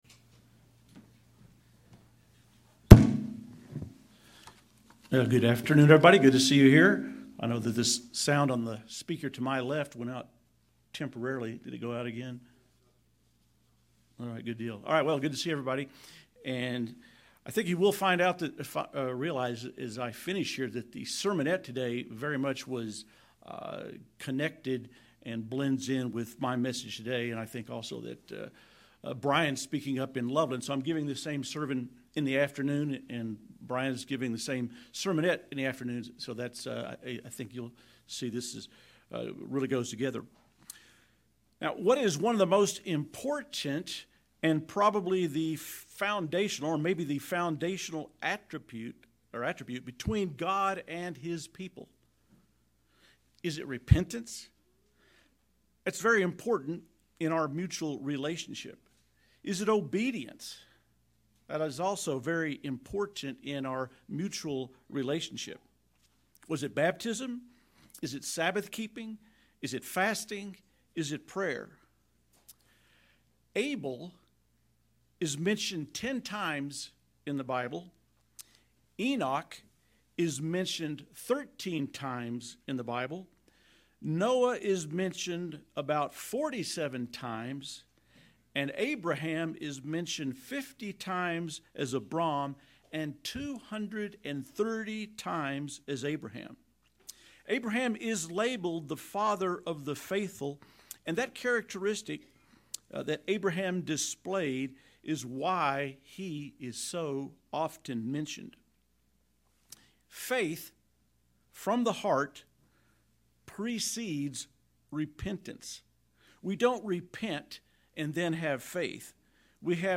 Sermons
Given in Colorado Springs, CO Denver, CO Loveland, CO